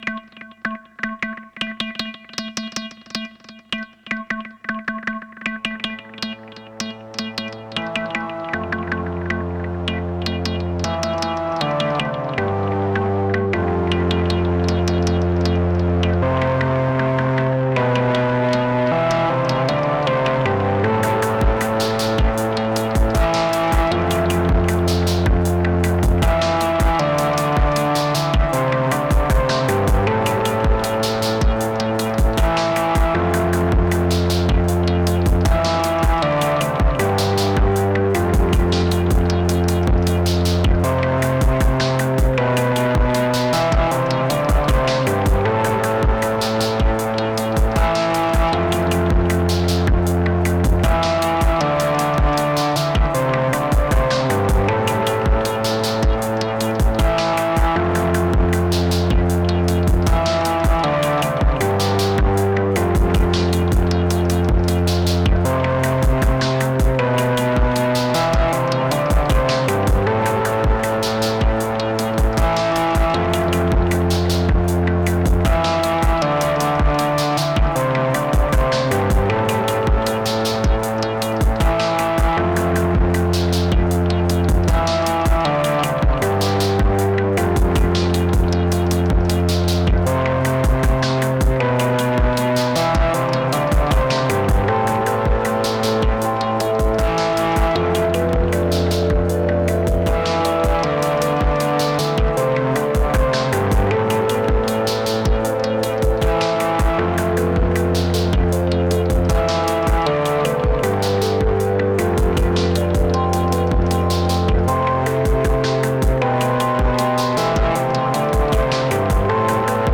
Been using my syntakt to make some dark vibey triphop/ambient loopy stuff this week and these songs could really be more fully formed but I love the sounds I’m getting.
Syntakt with Peak and Artemis (guess which is which!)